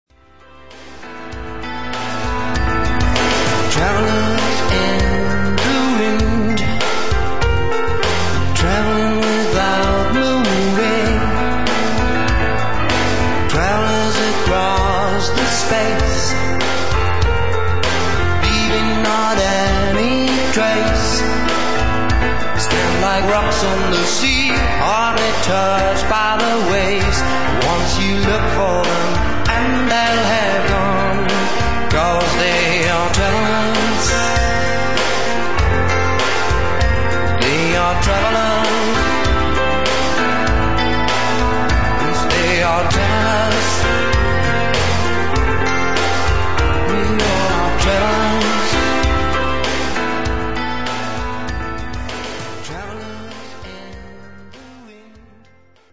anspruchsvolle internationale Tanzmusik und Dinnermusik in angenehmer Lautstärke für Familienfeiern
• Pianist / Orgelspieler
• Sänger/in
tanzmusik_rock.mp3